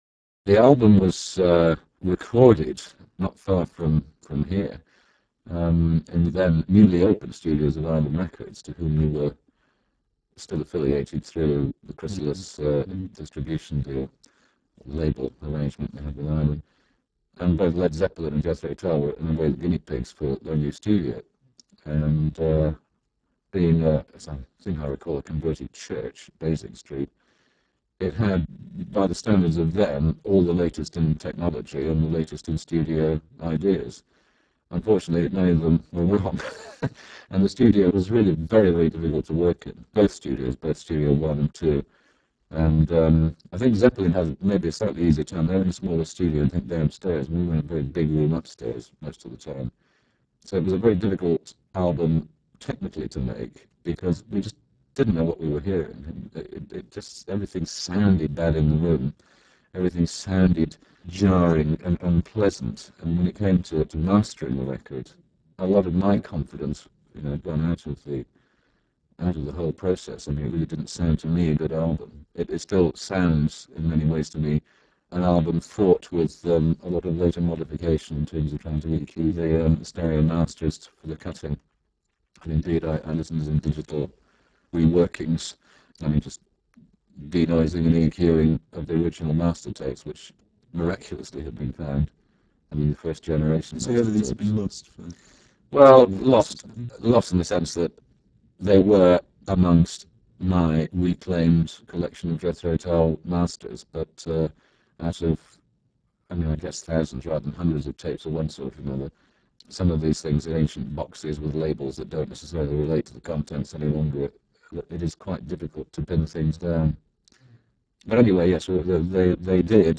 interview_uwb_q1.spx